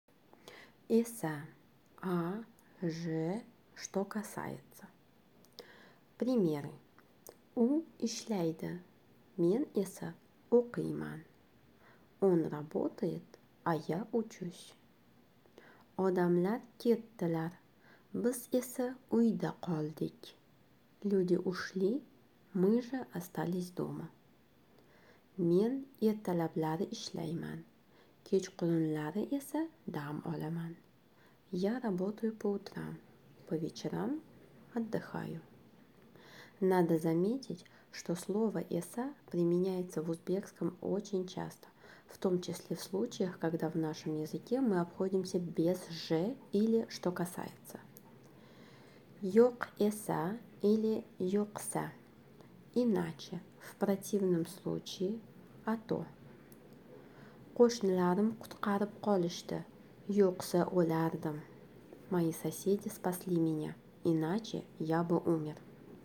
Узбекский язык - аудиоуроки